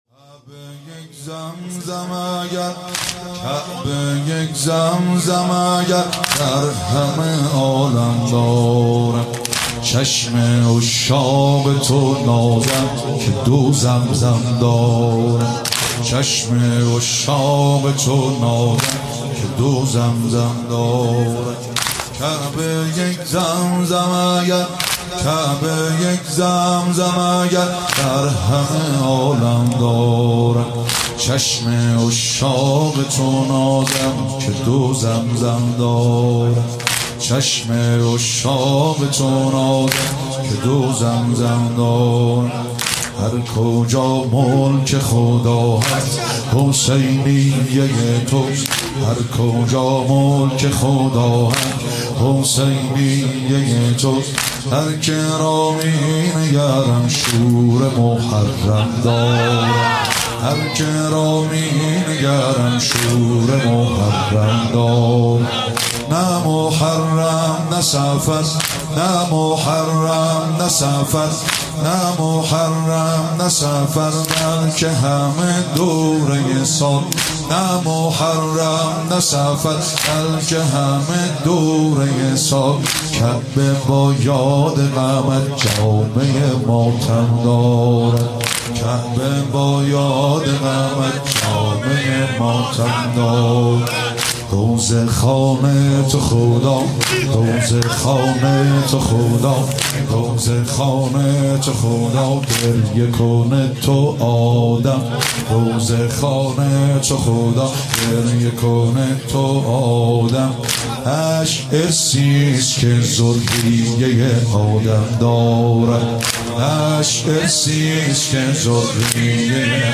شب چهارم ایام فاطمیه بهمن ماه 1397